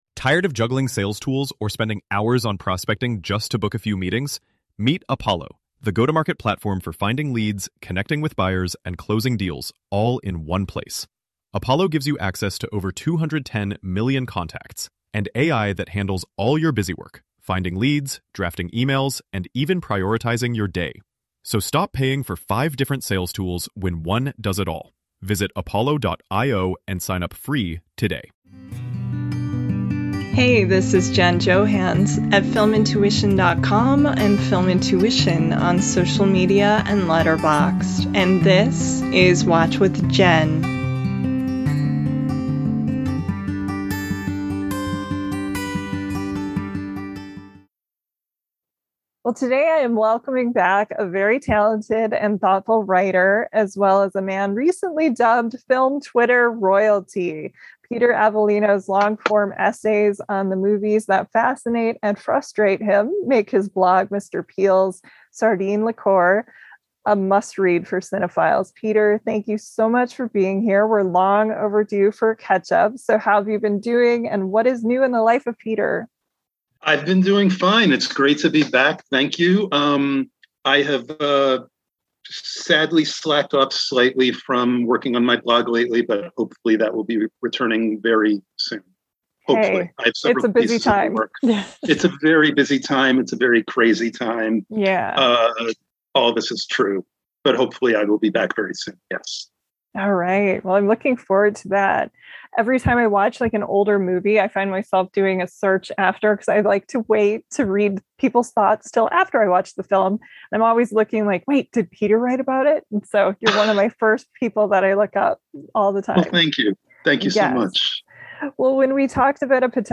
Theme Music: Solo Acoustic Guitar